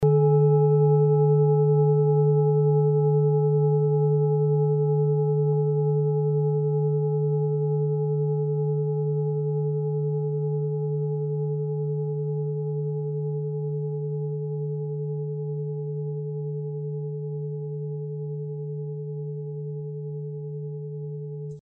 Klangschale TIBET Nr.35
Sie ist neu und ist gezielt nach altem 7-Metalle-Rezept in Handarbeit gezogen und gehämmert worden.
(Ermittelt mit dem Filzklöppel)
Der Saturnton liegt bei 147,85 Hz und ist die 37. Oktave der Umlauffrequenz des Saturns um die Sonne. In unserer Tonleiter liegt dieser Ton nahe beim "D".
klangschale-tibet-35.mp3